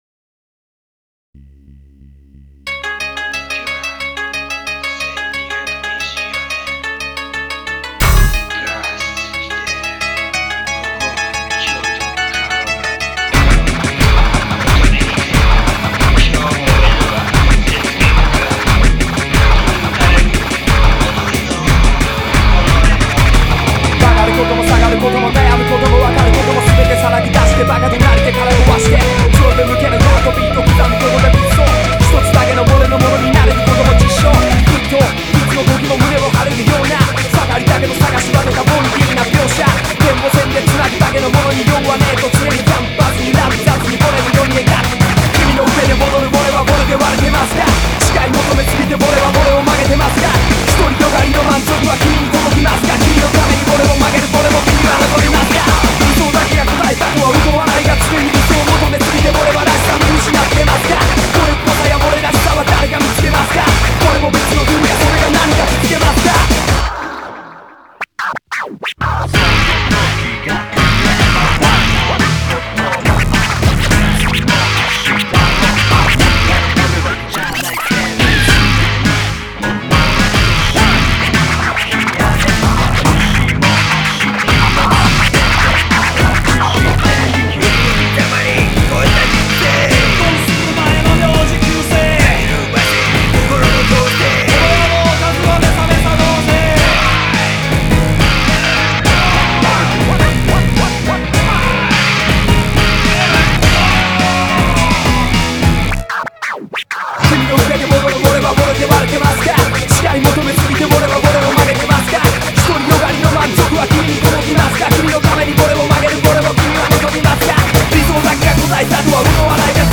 BPM105-180